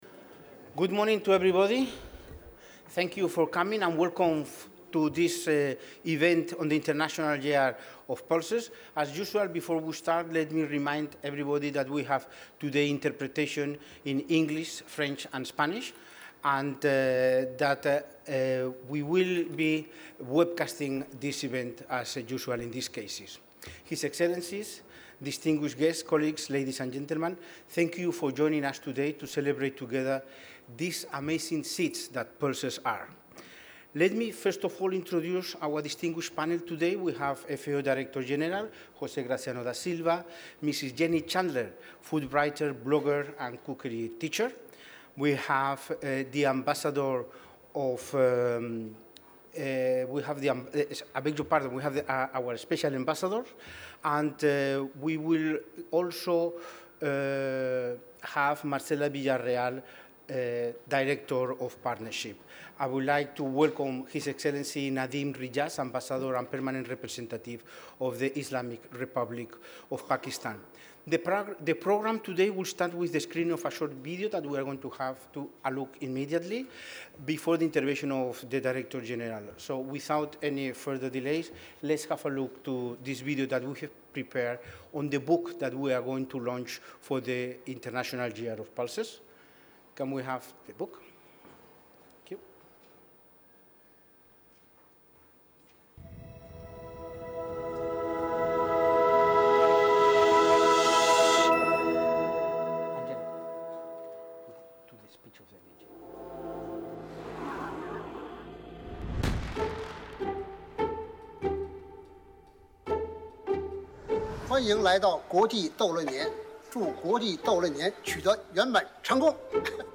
Full recording of the nomination ceremony
statements by FAO Director-General, José Graziano da Silva